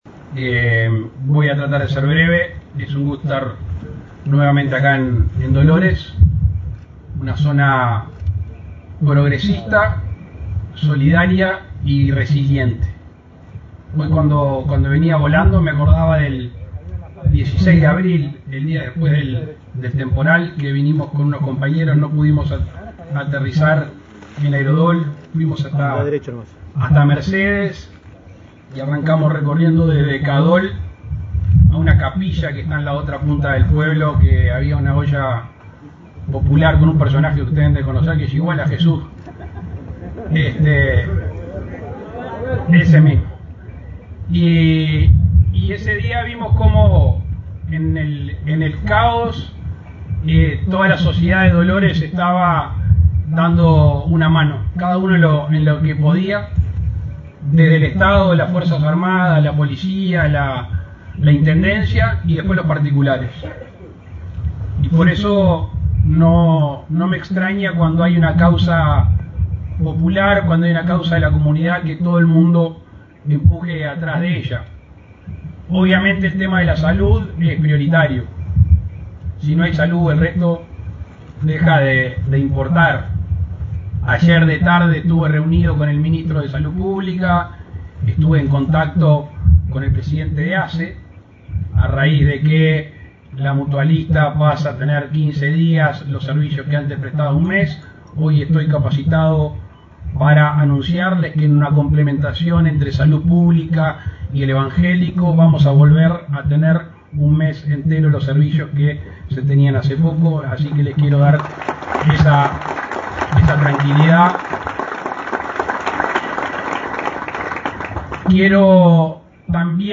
Palabras del presidente Luis Lacalle Pou
El presidente Luis Lacalle Pou encabezó este martes 16 en Dolores, Soriano, la inauguración de la cosecha de trigo, en ceremonia convocada por la